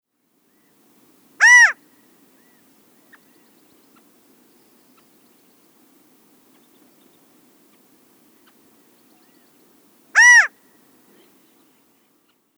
А вот как звучит крик калифорнийского хохлатого перепела